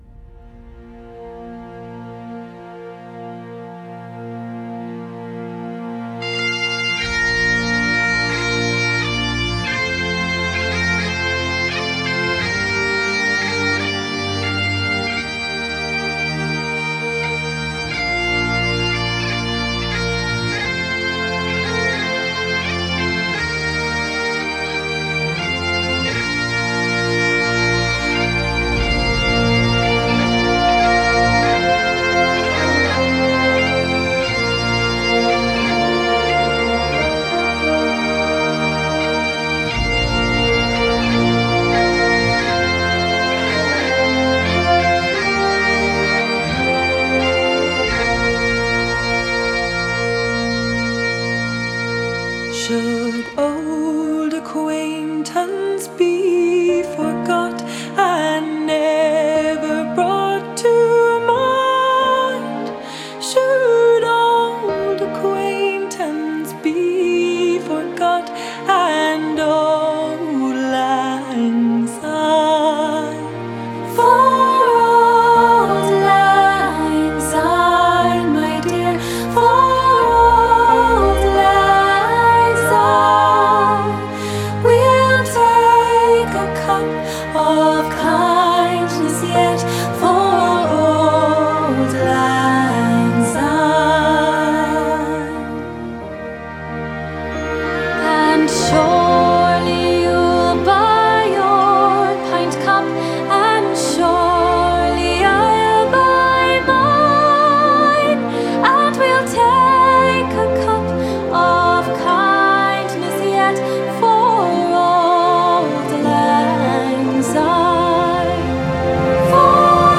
US • Genre: Celtic